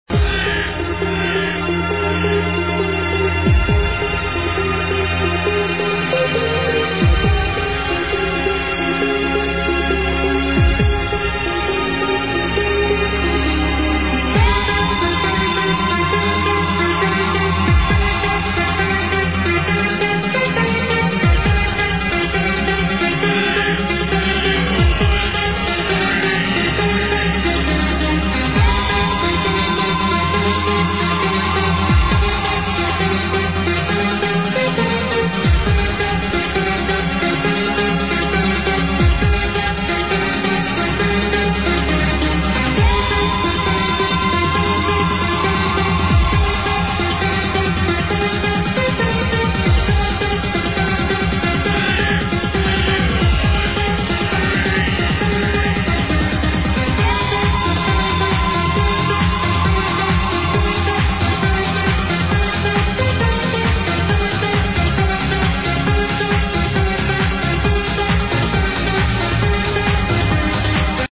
s31- more trance
Very famous old skool tune mate but I cant recall it now...